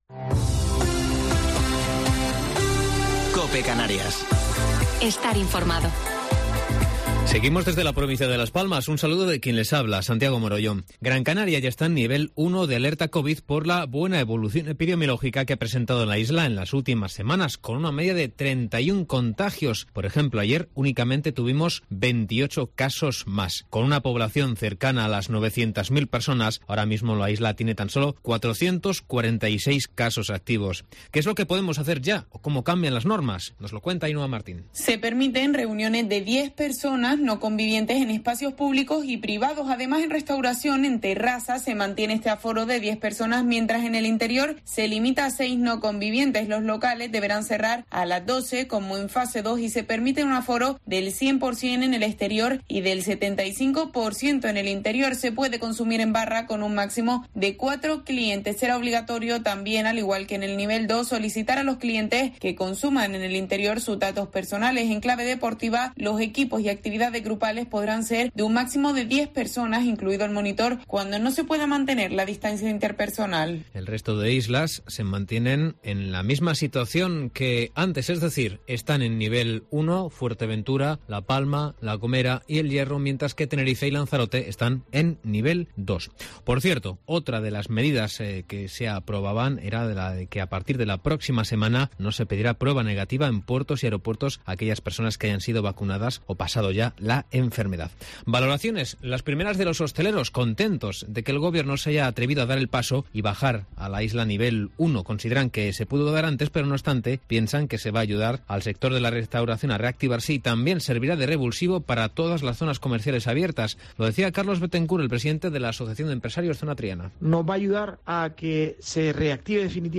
Informativo local 28 de Mayo del 2021